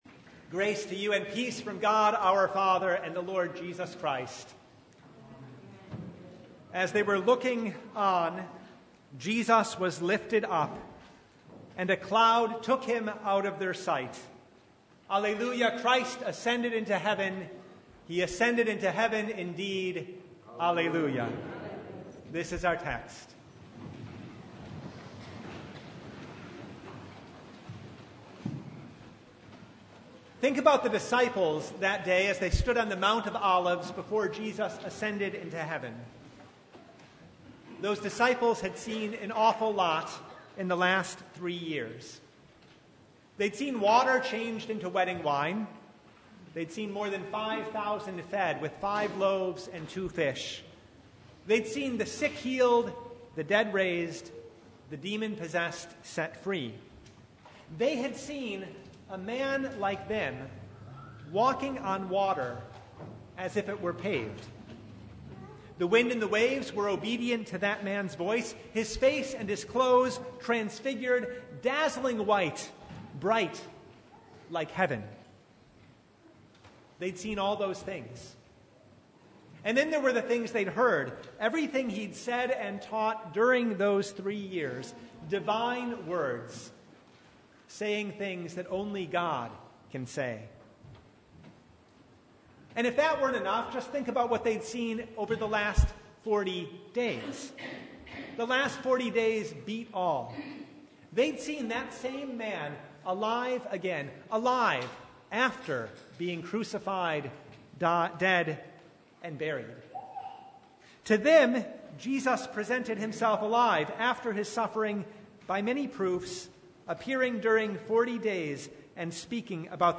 Sermon Only « God Came Down Martha
Sermon-June-2-2019.mp3